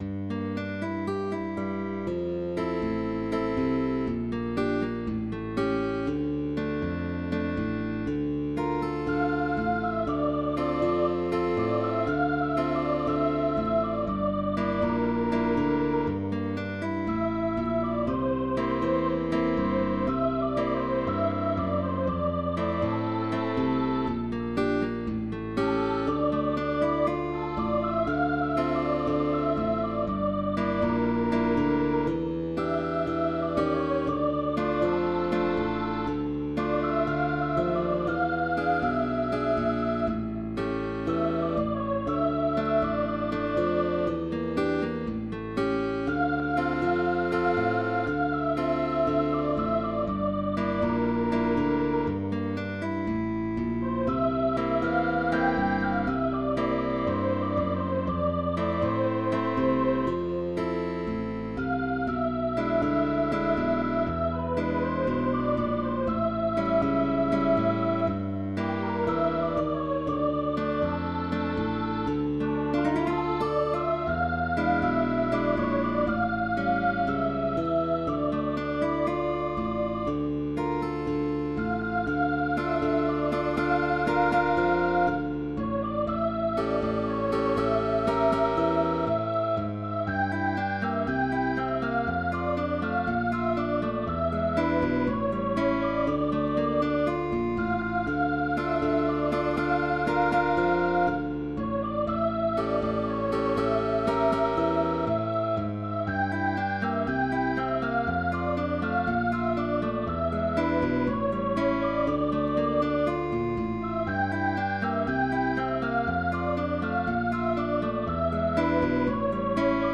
is a bolero-son composed in 1929
With optional bass.